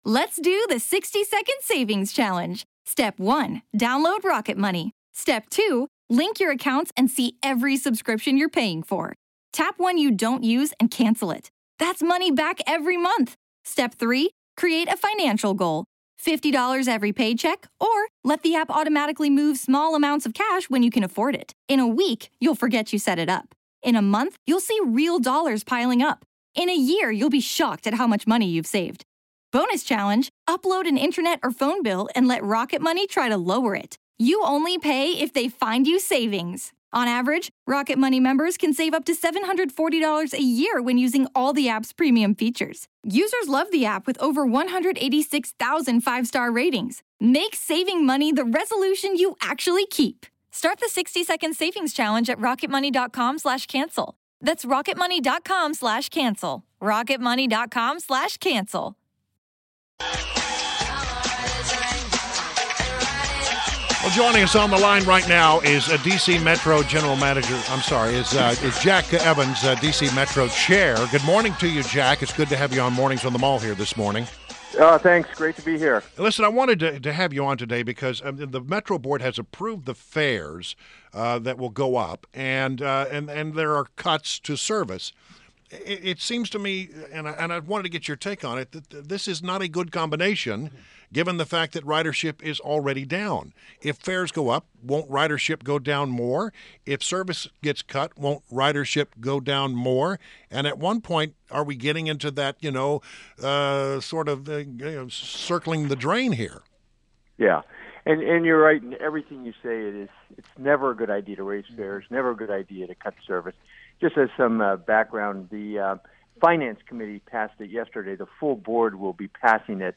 WMAL Interview - JACK EVANS - 03.10. 17
INTERVIEW – JACK EVANS – DC COUNCILMEMBER AND DC METRO BOARD CHAIRMAN